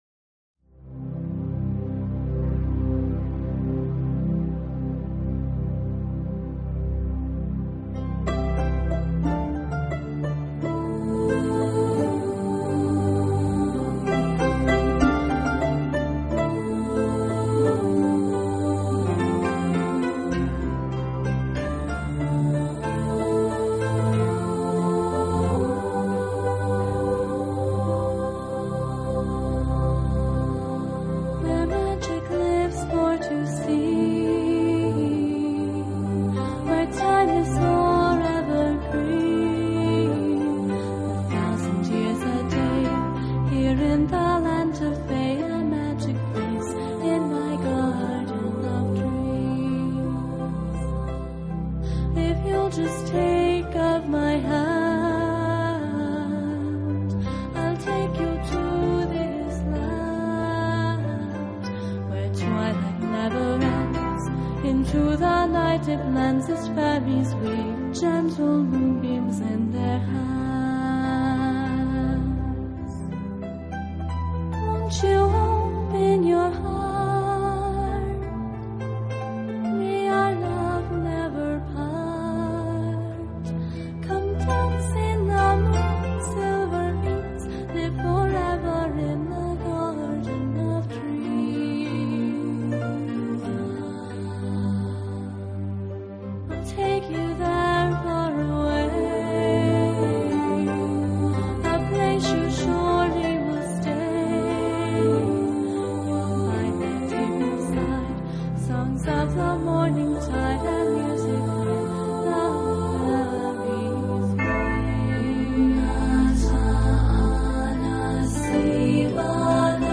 音乐类型：NEW AGE/Celtics
女声轻柔曼妙，音乐温馨静谧。